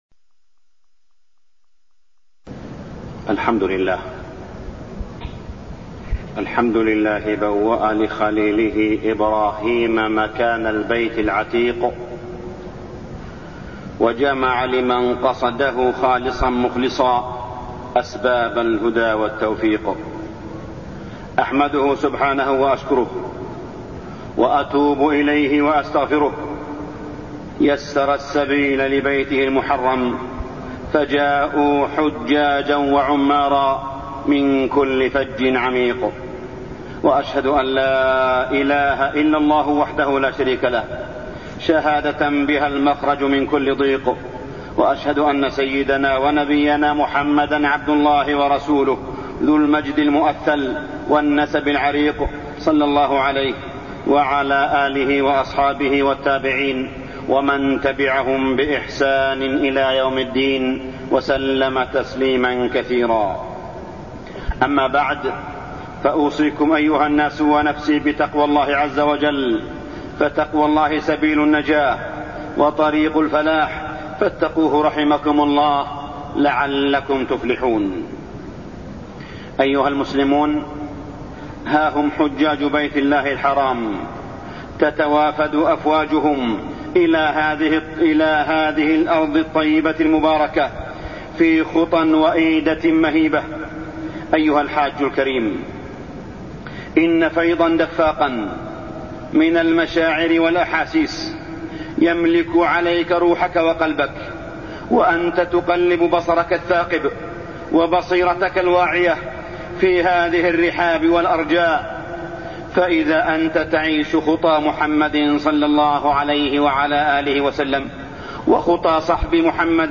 تاريخ النشر ٢٢ ذو القعدة ١٤٢١ هـ المكان: المسجد الحرام الشيخ: معالي الشيخ أ.د. صالح بن عبدالله بن حميد معالي الشيخ أ.د. صالح بن عبدالله بن حميد الحج وآدابه